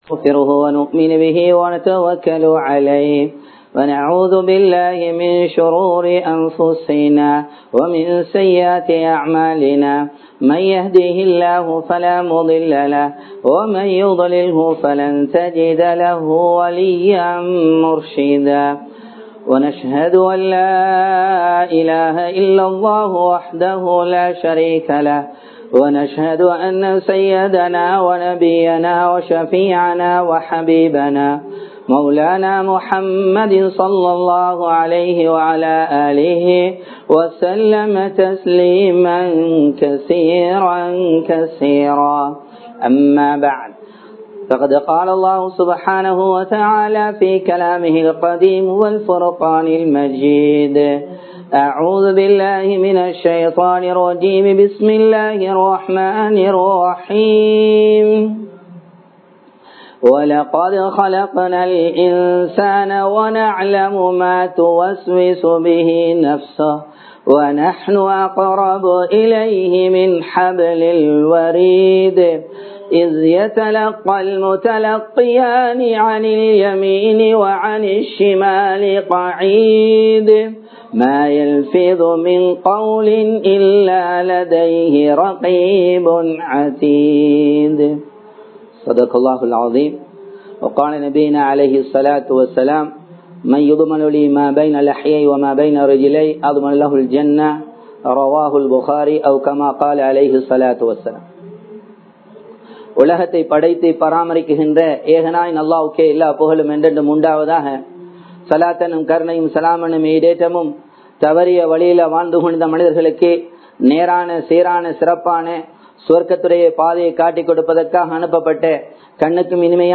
நாவின் விளைவுகள் | Audio Bayans | All Ceylon Muslim Youth Community | Addalaichenai
Colombo 03, Kollupitty Jumua Masjith